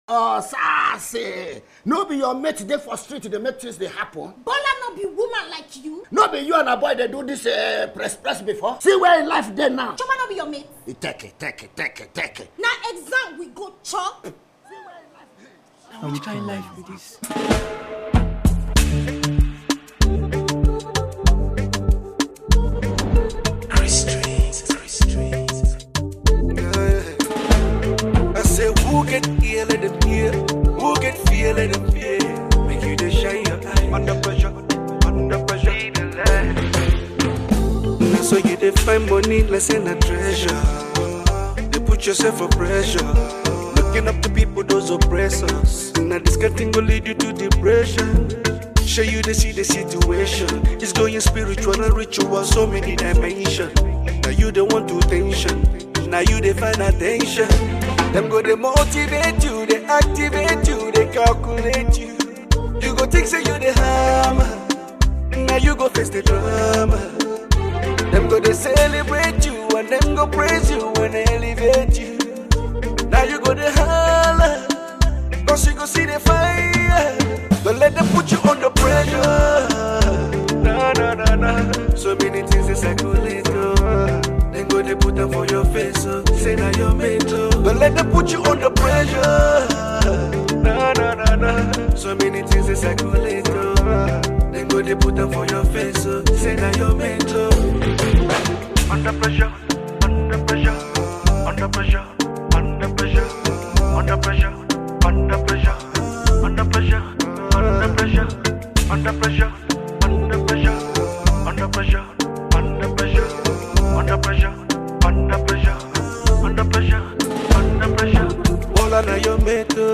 the acclaimed Afrobeat singer-songwriter
Known for his distinctive voice and powerful delivery
With its infectious energy and relatable theme